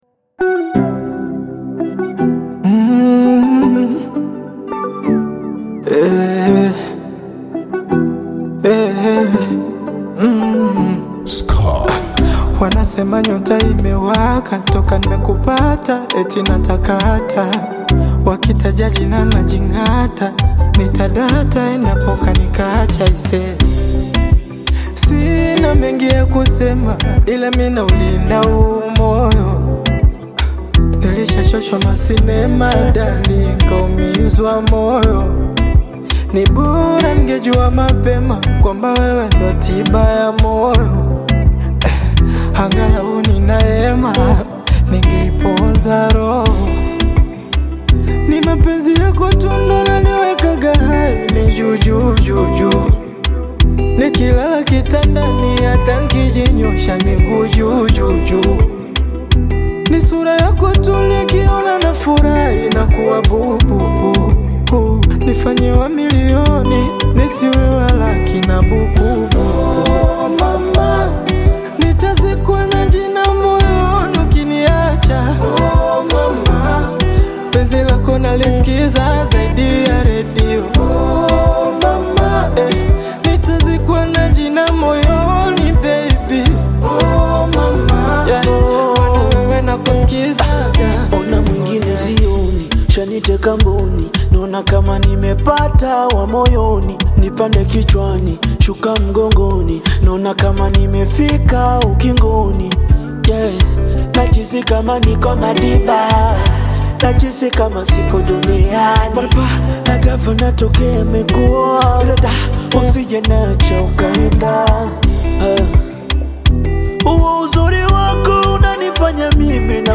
upbeat Afro-Beat/Bongo Flava single
catchy rhythms and smooth vocals
Genre: Bongo Flava